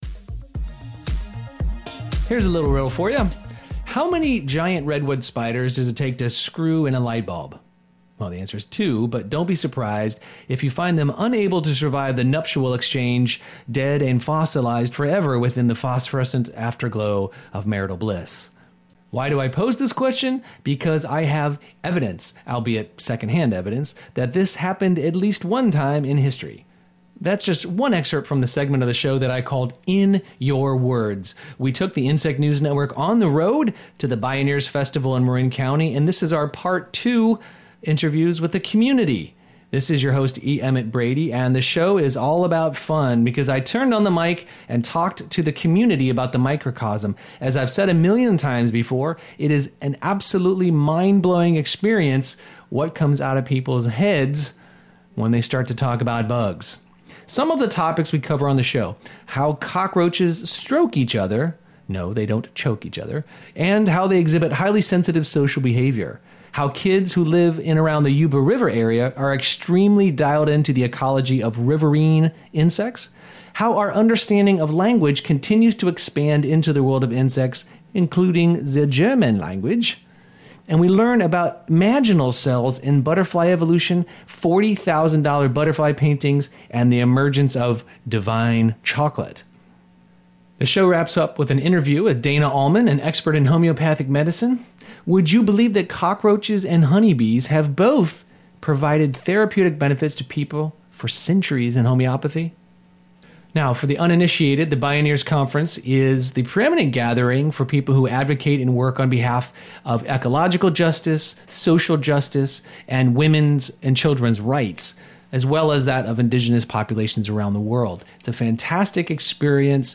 Today's show is some of the primo excerpts from the second part of my Bioneers 2012 edition of the Insect News Network.